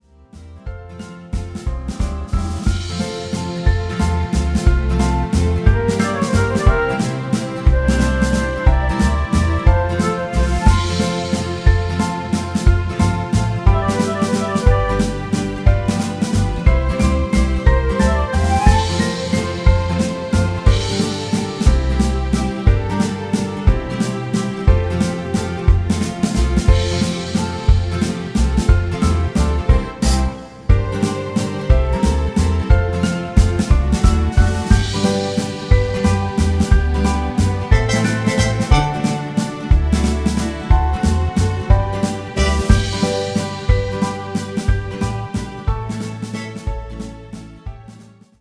(sans choeurs)